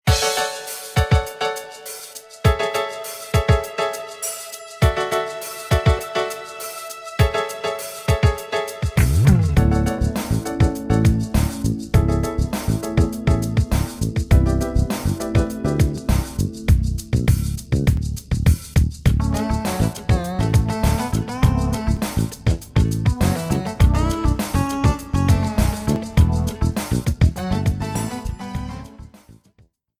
Credits music
Reduced length to 30 seconds, with fadeout.